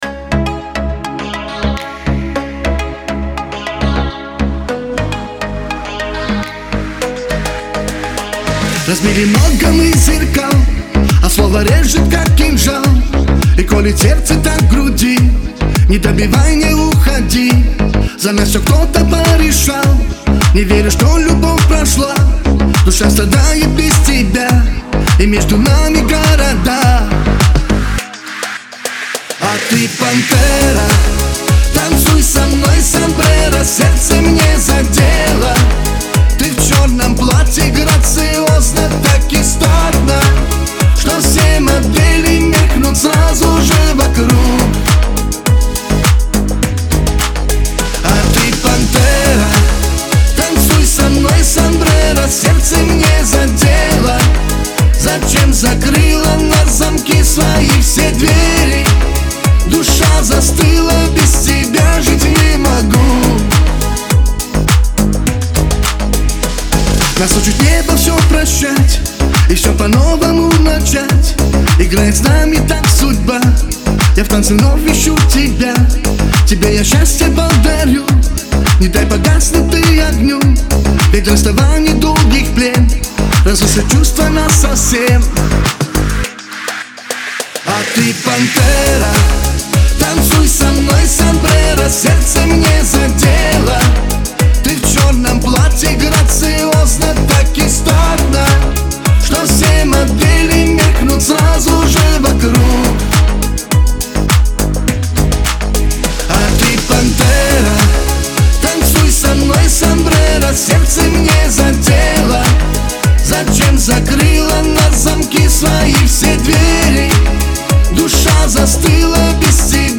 Кавказ – поп
грусть , Лирика , эстрада